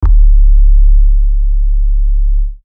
Redd808.wav